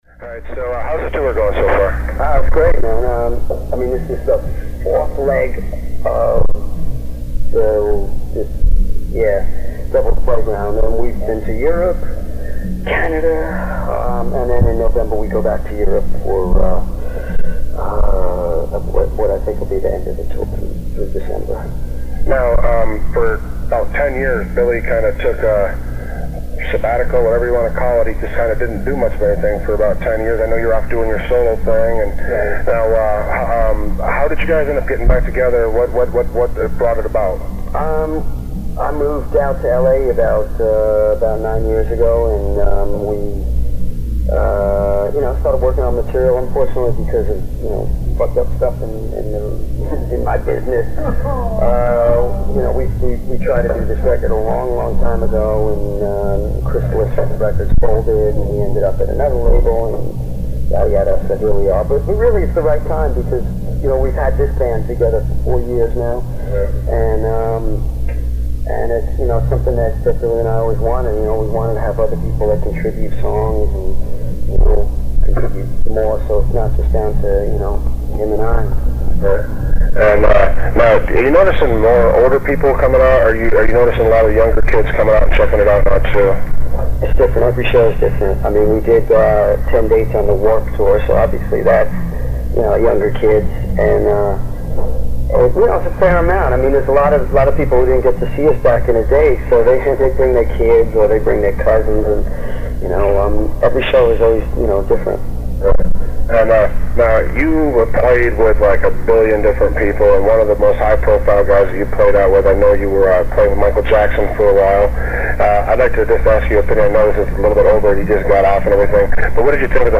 SteveStevensChat.mp3